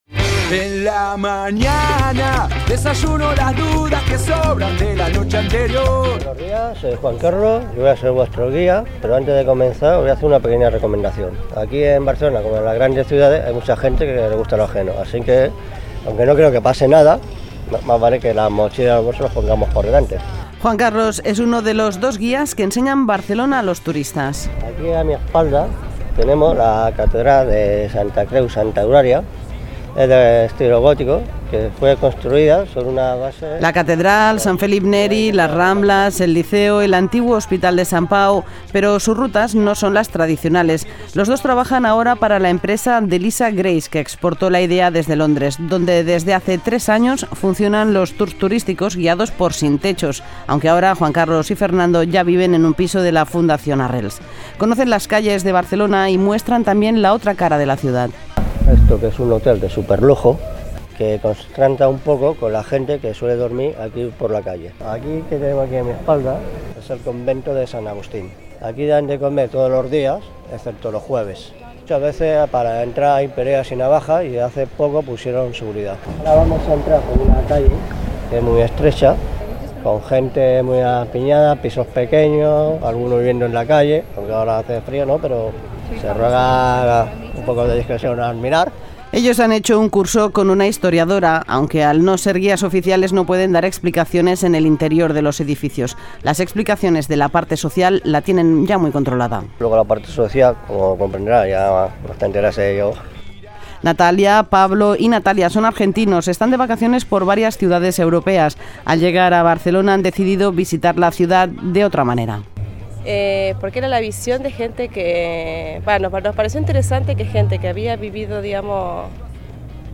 Reportaje: Turismo con 'sin techo' - Radio 5 TN, 2014